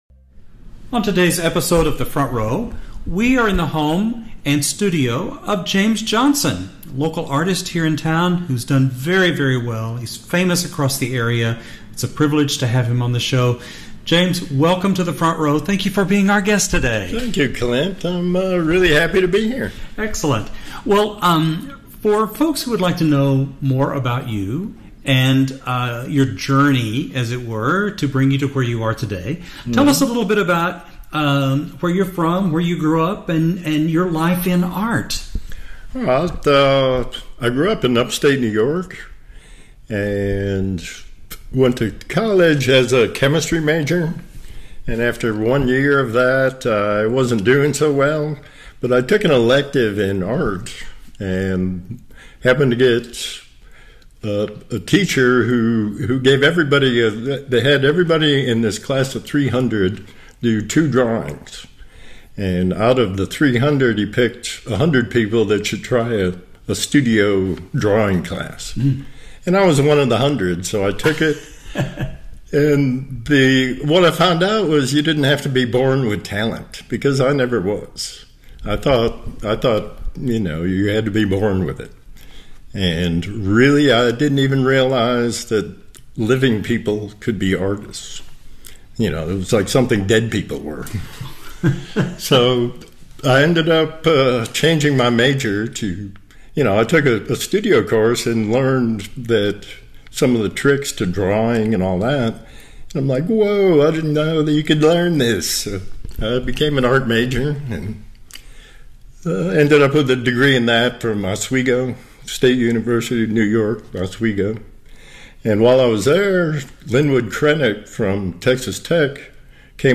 KLZK interview.mp3